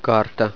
Fai clic sulla parola per sentire la pronuncia.